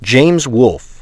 1 channel
WOLFBITE.WAV